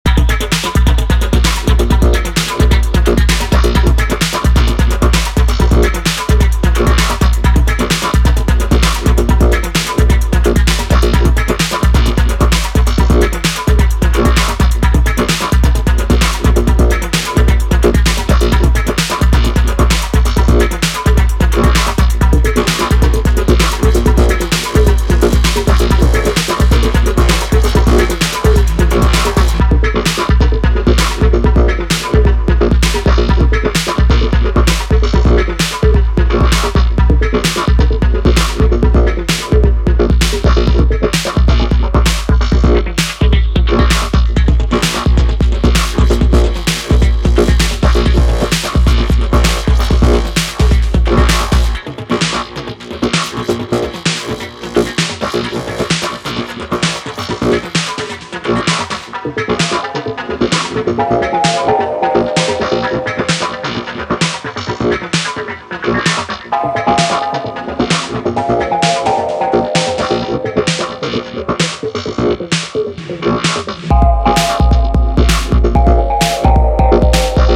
ビキビキと放電するかの如き激しい刺激のシーケンスを特徴とする4トラックス。
後半に登場する浮遊感のあるコードで抜きを作った構成が巧みなエレクトロ